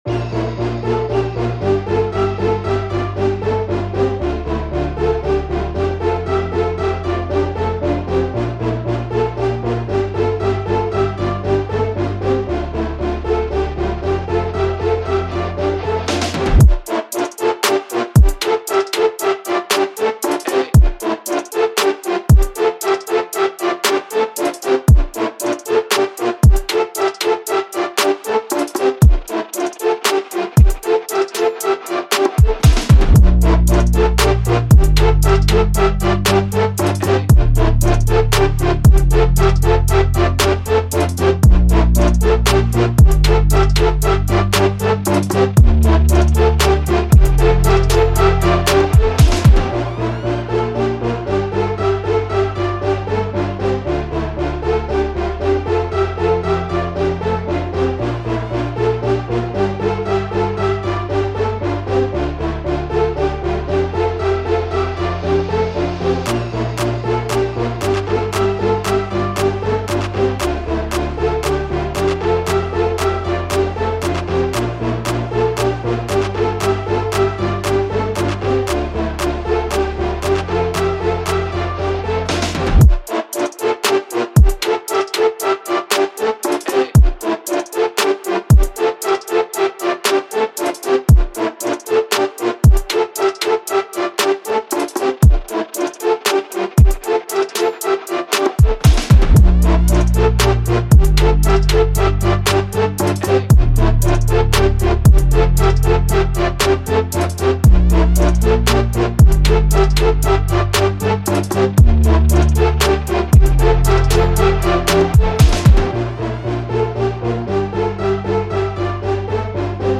• 惊人的详细技术 将使您的音轨保持流畅和有趣，从而获得长期的聆听乐趣。